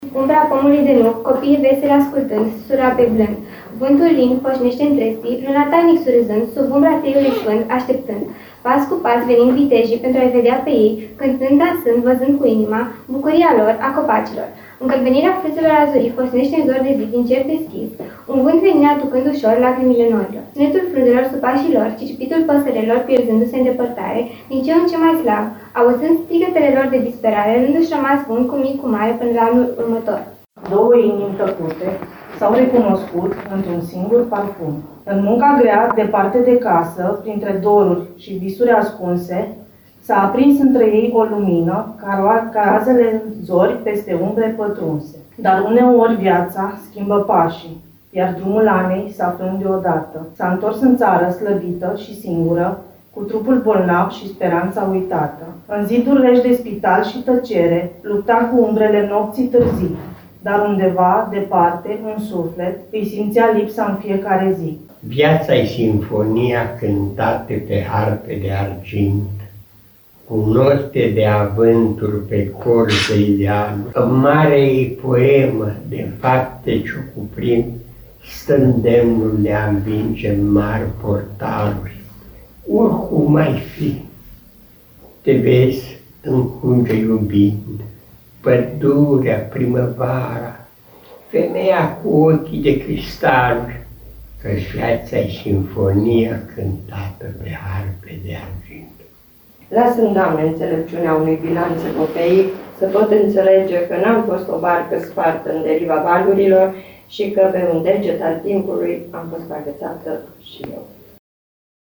Am stat de vorbă cu unii dintre ei iar pe alții i-am ascultat recitând.